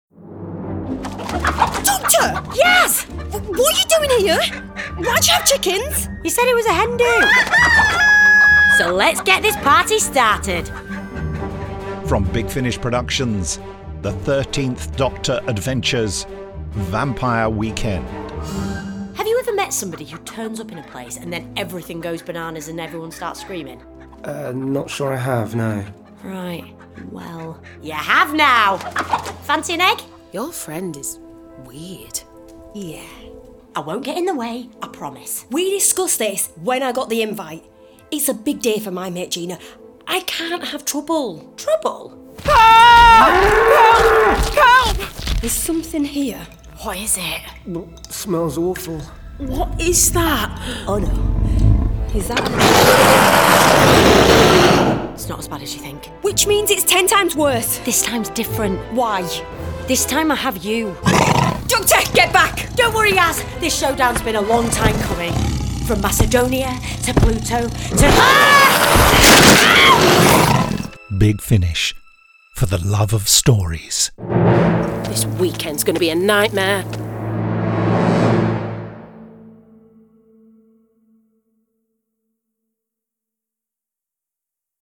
Award-winning, full-cast original audio dramas
Starring Jodie Whittaker Mandip Gill